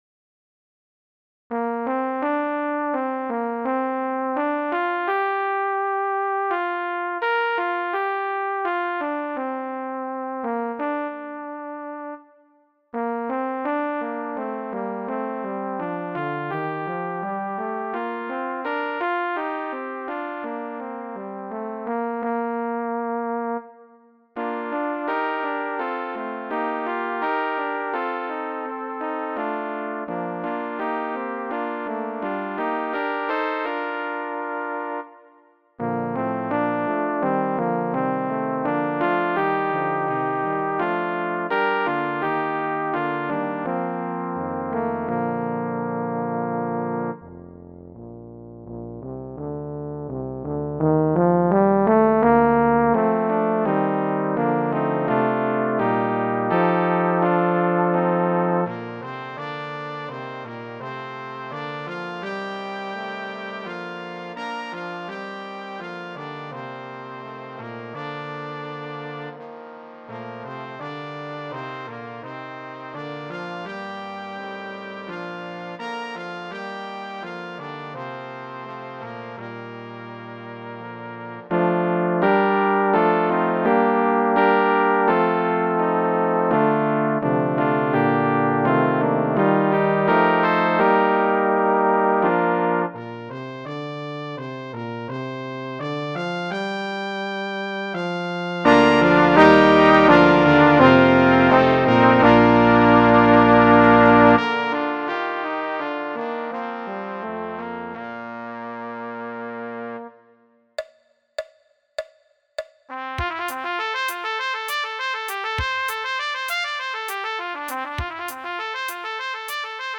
Music for Brass
A lyrical slow melody followed by a lively reel.